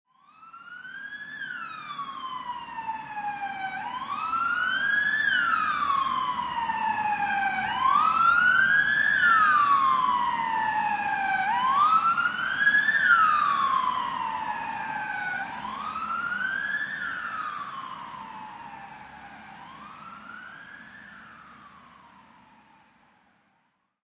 Download Ambulance Siren sound effect for free.
Ambulance Siren